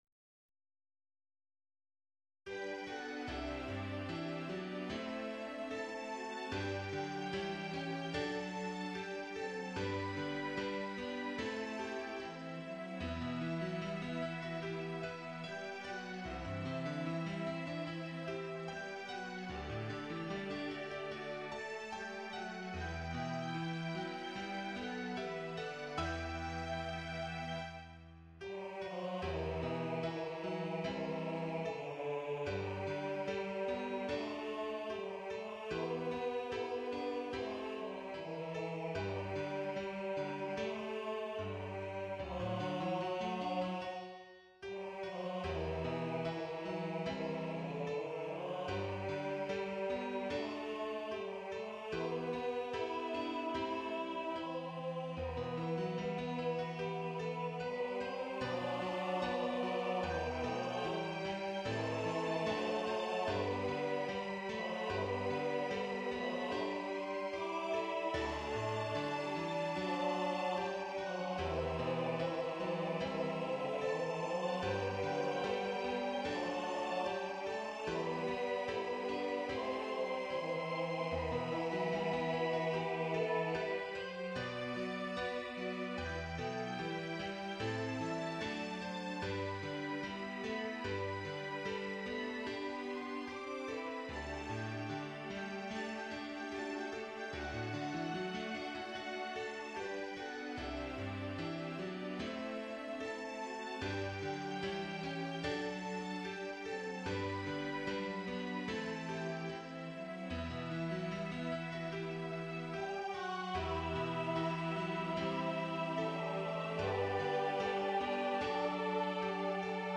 Download Duet Sheet Music